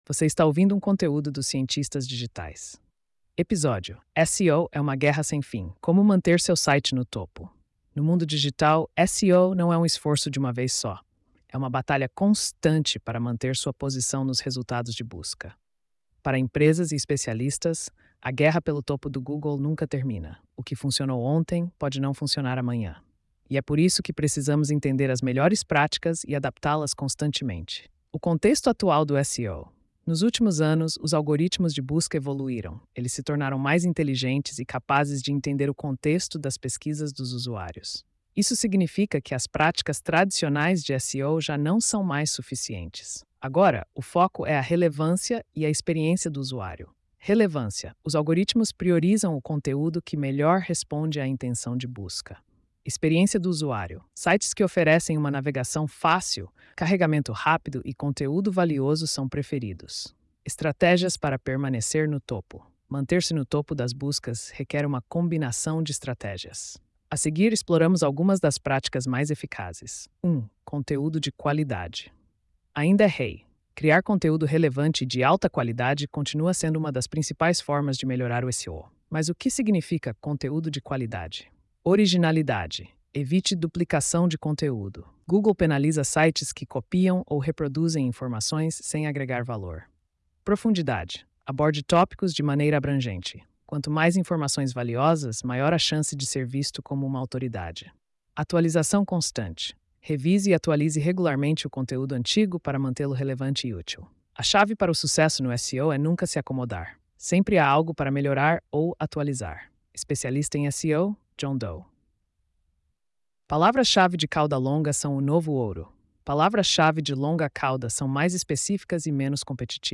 post-2473-tts.mp3